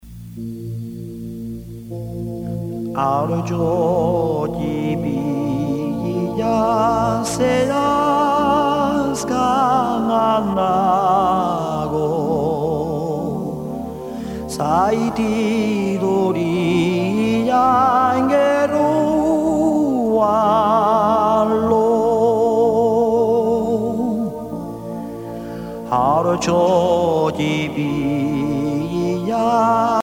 enfantine : berceuse
Pièce musicale éditée